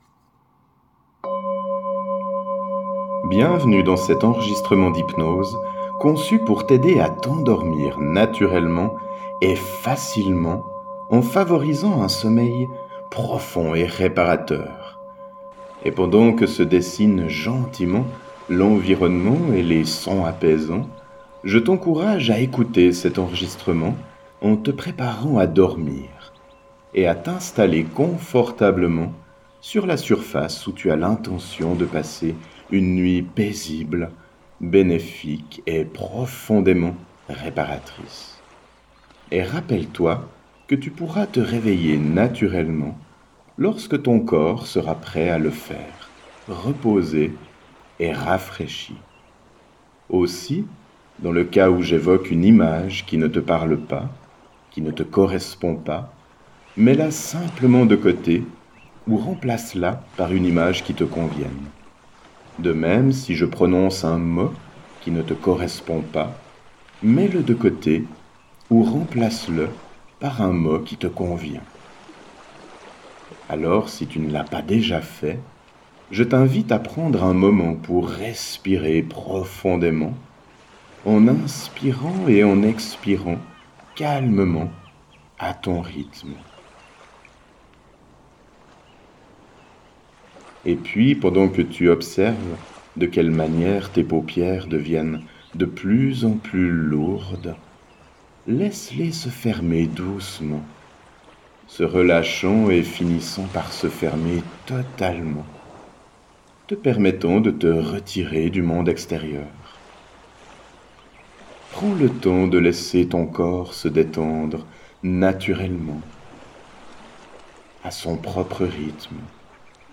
Hypnose pour dormir
hypnose-sommeil-mer.mp3